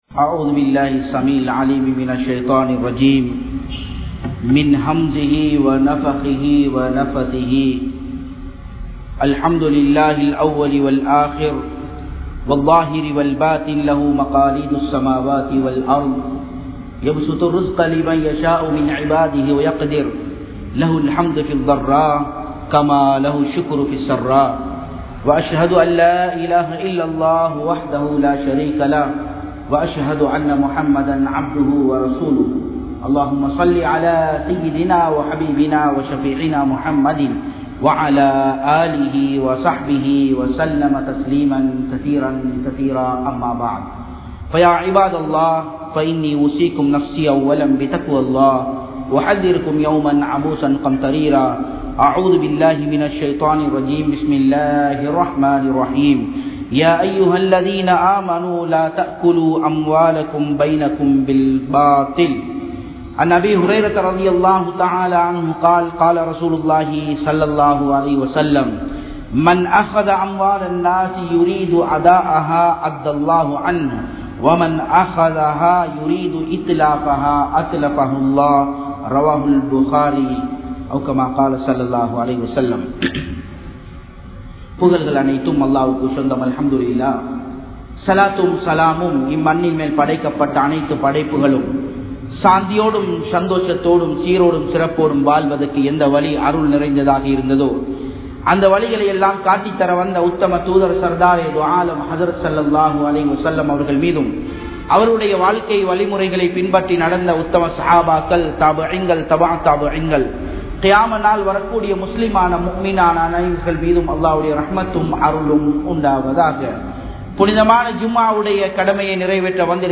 Kadanai Niraiveattra Villaiya? (கடனை நிறைவேற்றவில்லையா?) | Audio Bayans | All Ceylon Muslim Youth Community | Addalaichenai